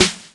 cch_07_snare_one_shot_high_noise_paper.wav